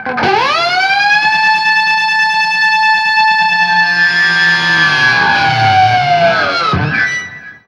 DIVEBOMB12-L.wav